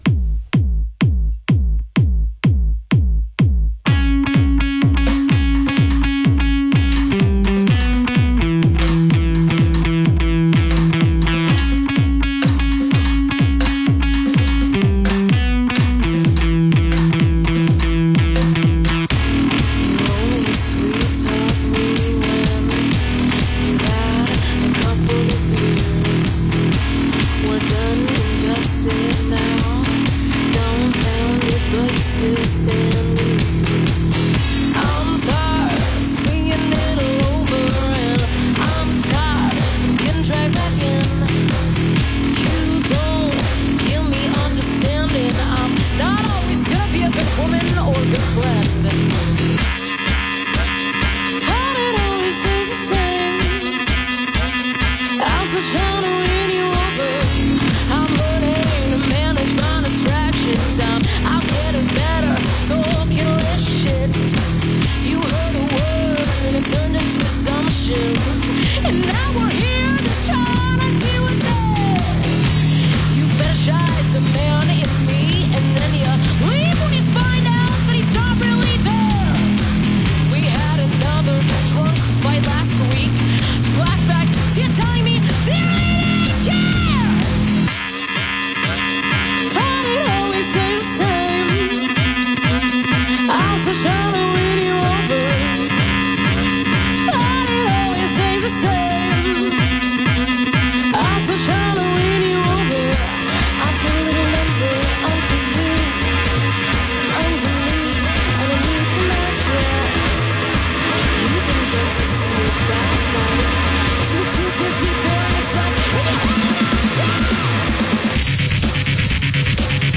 electro-punk band
vocals
guitar
drums